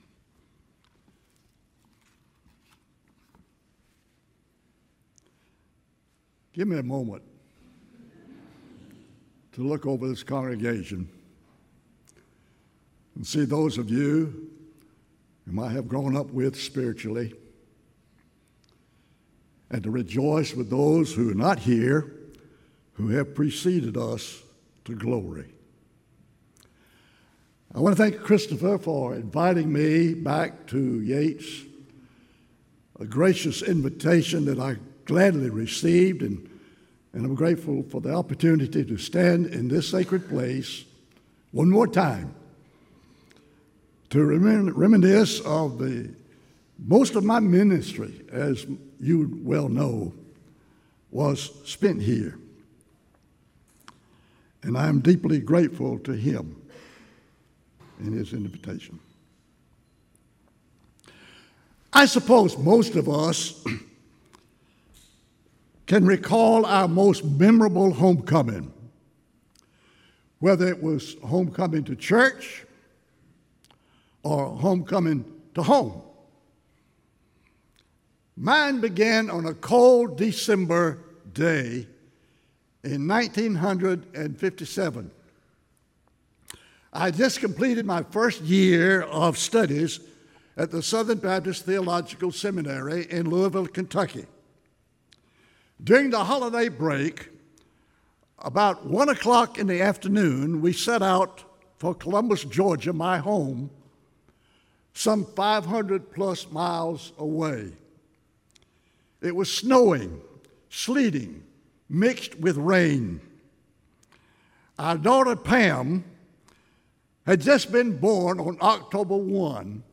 Revelation 21-22 Service Type: Traditional Service Homecoming is both now and eternal.